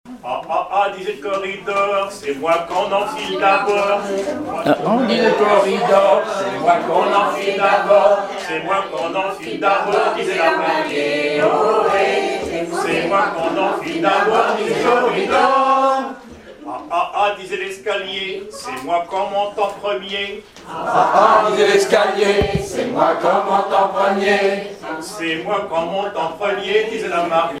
Informateur(s) Club d'anciens de Saint-Pierre association
circonstance : fiançaille, noce
Genre énumérative
Pièce musicale inédite